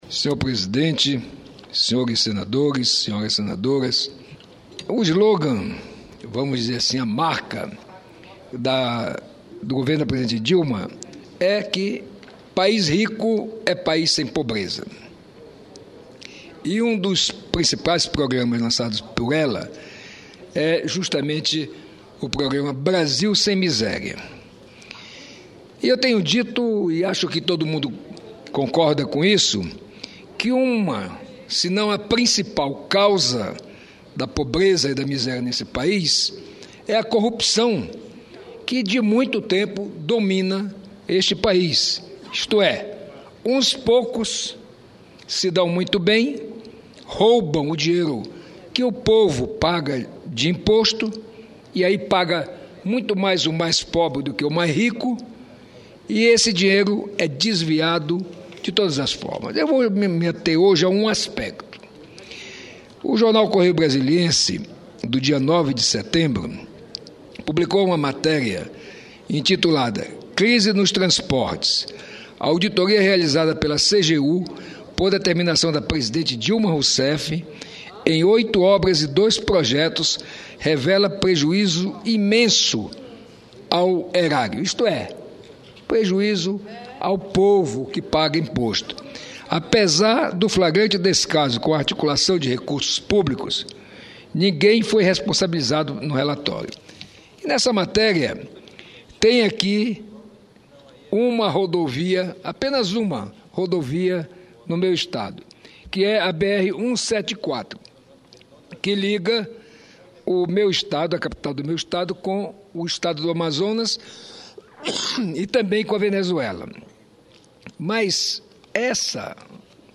Mozarildo comenta possíveis casos de corrupção em obras do Dnit em RR O senador Mozarildo Cavalcanti (PTB-RR) ressaltou a instalação do Fórum Estadual de Combate à Corrupção em Roraima. O senador citou a matéria do Correio Brasiliense sobre possíveis desvios de recursos públicos em obras do Dnit, destacando como exemplo a obra da BR 174, que liga Roraima à Manaus e à Venezuela. Segundo ele a corrupção é a principal causa da miséria no Brasil.